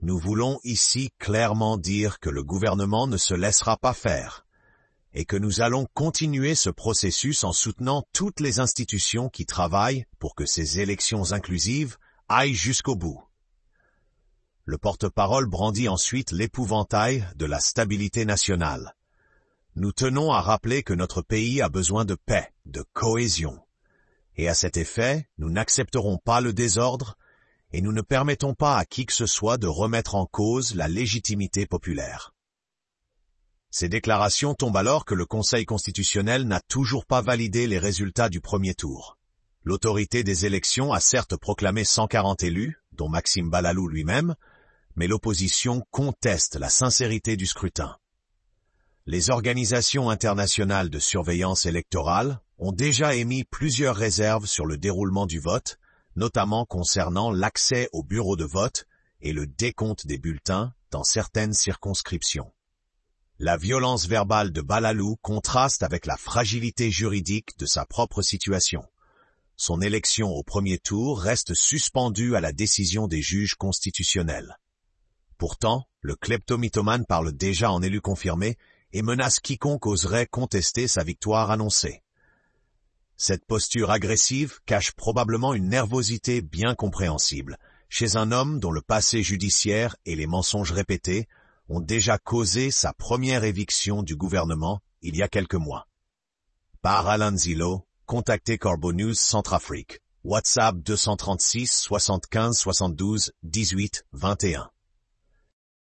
Le clepto-mythomane Maxime Balalou, lors de sa conférence de presse, condamne les “gesticulations inutiles” du BRDC et les tentatives de perturbation des vaincus : “ | CNC